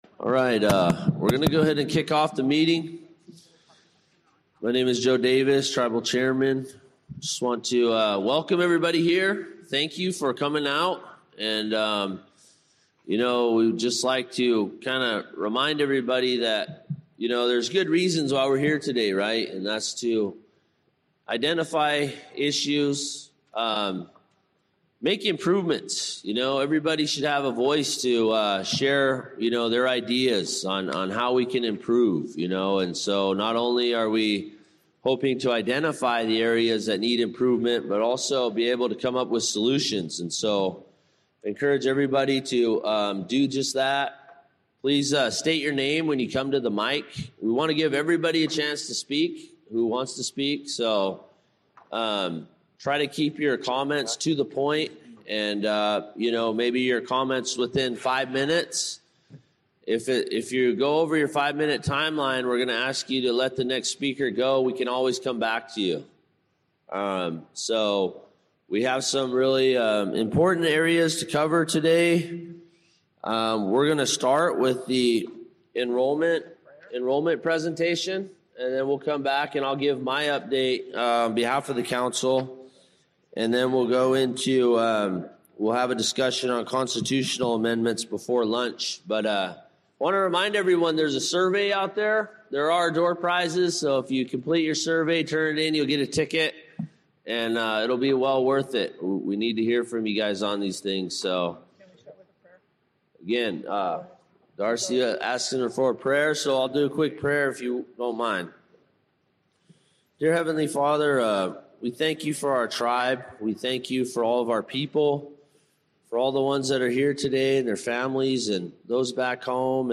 In this 1st hour of the six-hour recording of the Hoopa Valley General Meeting of 2-21-26 we hear the Chairman’s Opening Remarks.